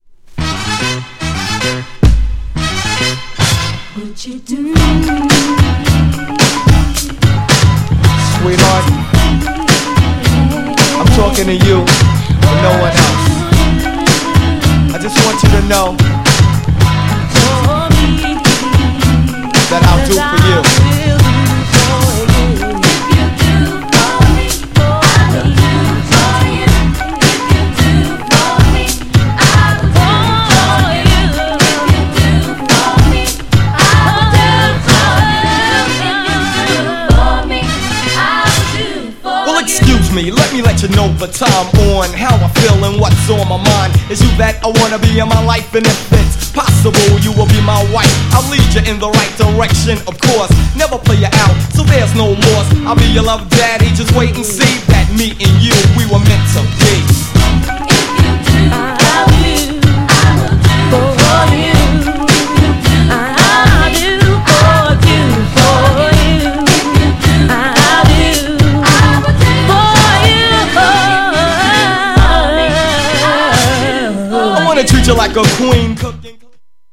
GENRE Hip Hop
BPM 106〜110BPM